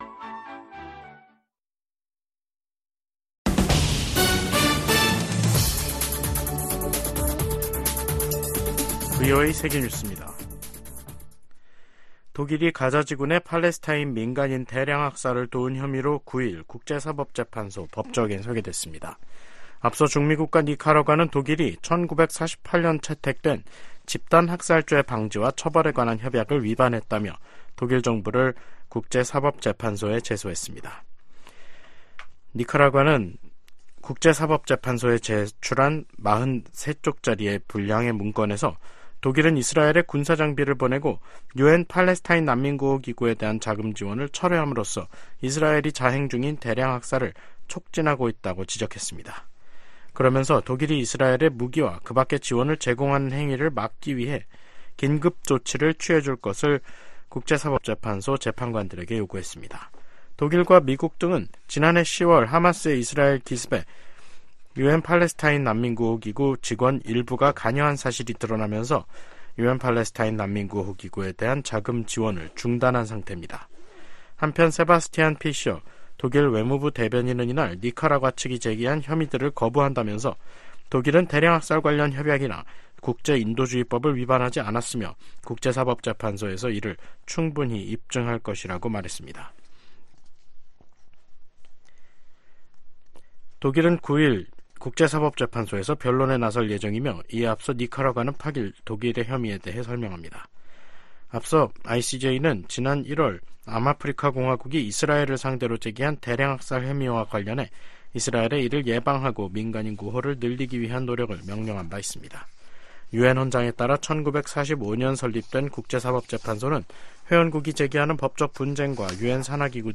VOA 한국어 간판 뉴스 프로그램 '뉴스 투데이', 2024년 4월 8일 3부 방송입니다. 전천후 영상 정보 수집이 가능한 한국의 군사정찰위성 2호기가 8일 발사돼 궤도에 안착했습니다. 북한 김일성 주석을 ‘가짜’로 판단하는 1950년대 미국 정부 기밀 문건이 공개됐습니다. 미 국무부는 러시아가 한국의 대러 독자제재에 반발,주러 한국대사를 불러 항의한 것과 관련해 한국의 제재 조치를 환영한다는 입장을 밝혔습니다.